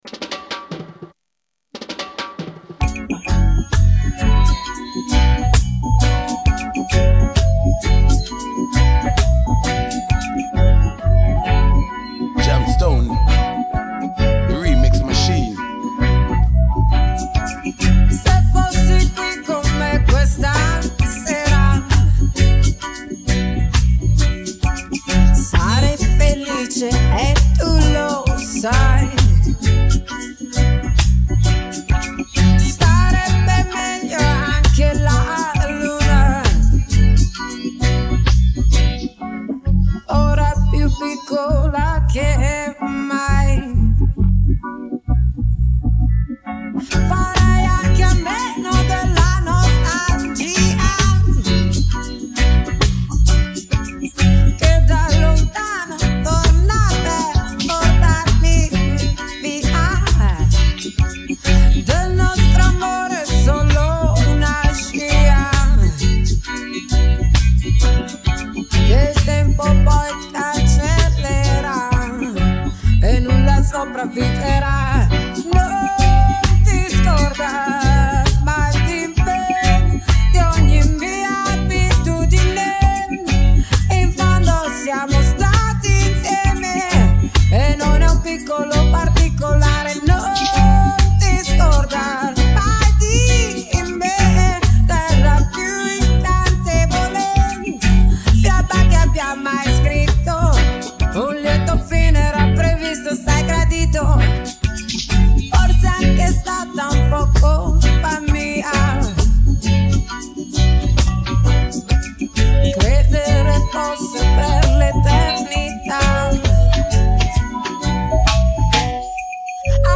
Vocal track
beat
refixed